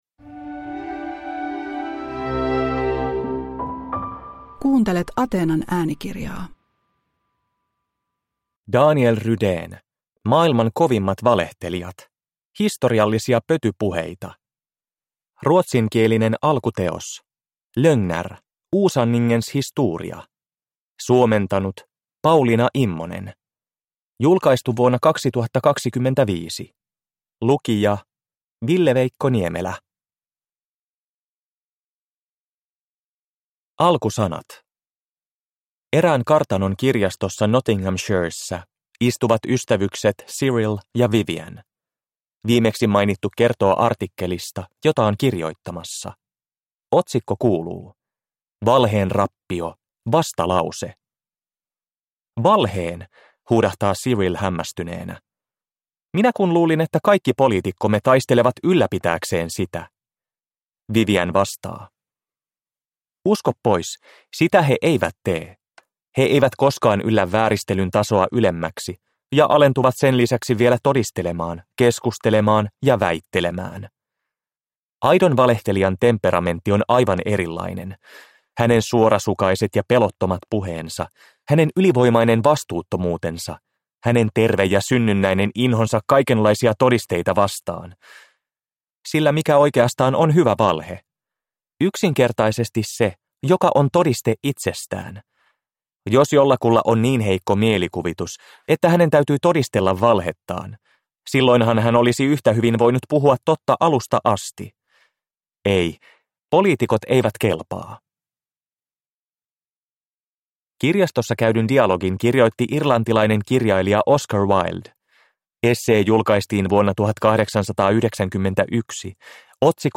Maailman kovimmat valehtelijat – Ljudbok